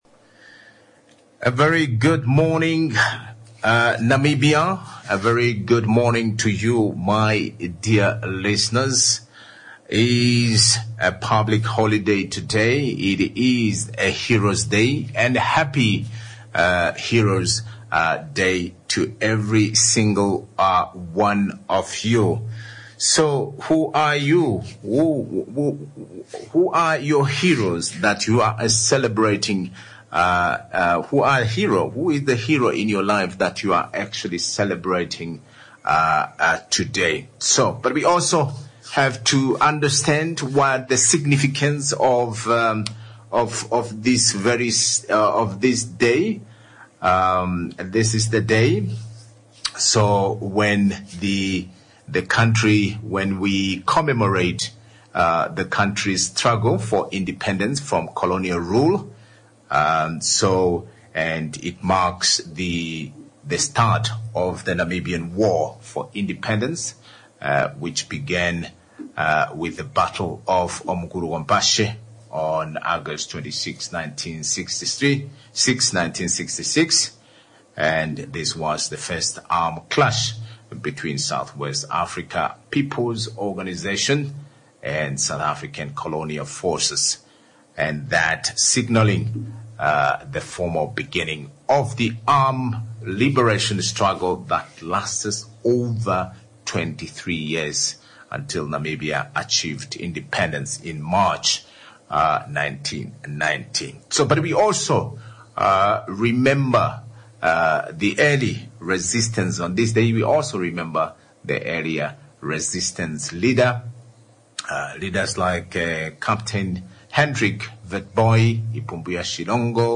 1. When We Talk About Heroes in Namibia, Do We Remember the Women?, Interviewed Inna Hengari, MP 2. 51% Local Ownership of Mines Could Have a Disastrous Consequences